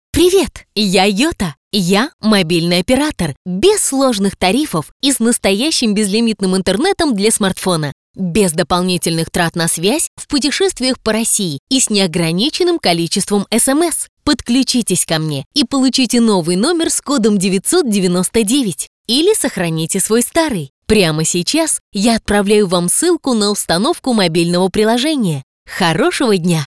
• Голос: Сопрано
• Женский
• Высокий
Автоответчик - Ярко